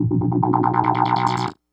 synthFX.wav